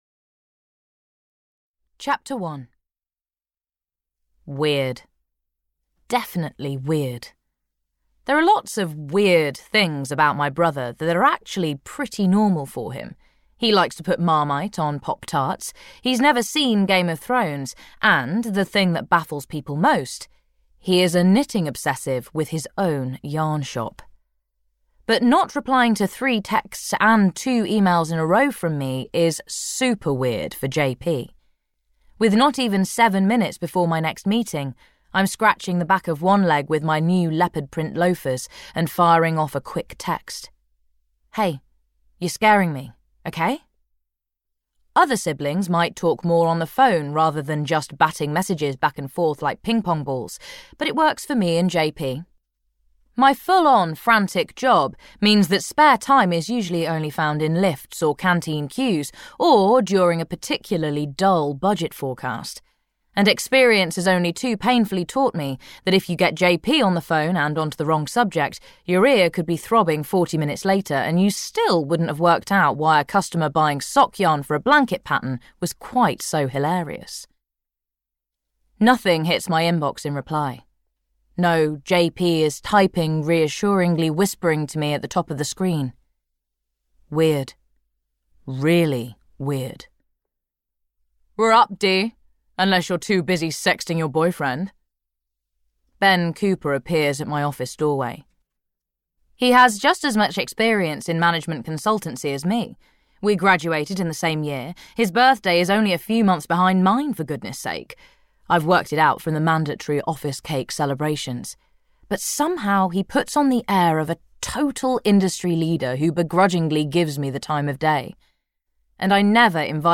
Audio knihaThe Woolly Hat Knitting Club (EN)
Ukázka z knihy